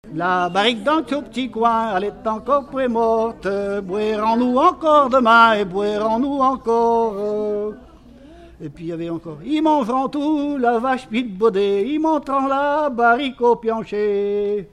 Mémoires et Patrimoines vivants - RaddO est une base de données d'archives iconographiques et sonores.
circonstance : bachique
Regroupement de chanteurs du canton
Pièce musicale inédite